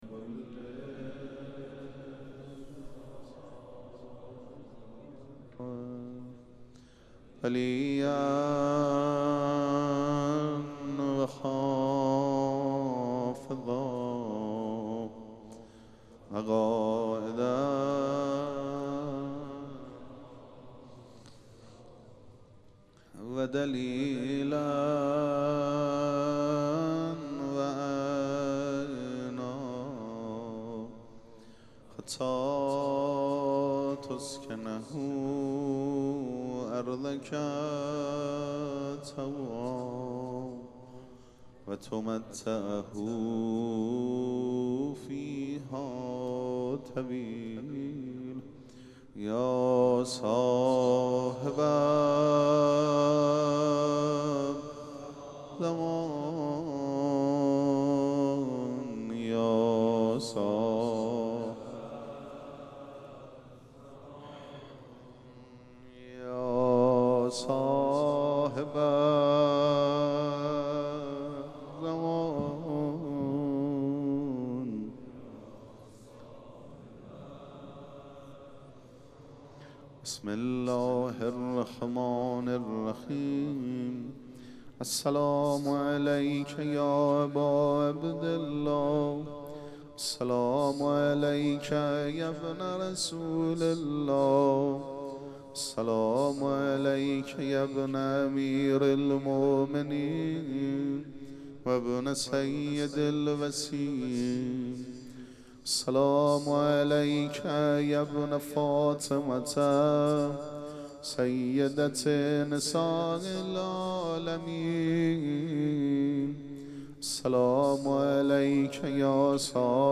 مراسم عزاداری شب هفتم محرم الحرام ۱۴۴۷
پیش منبر